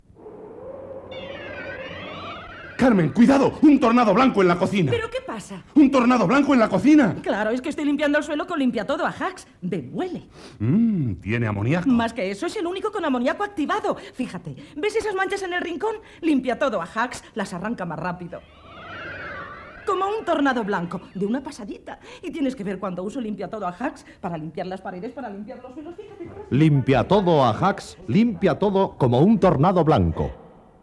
Publicitat Ajax "como un tornado blanco"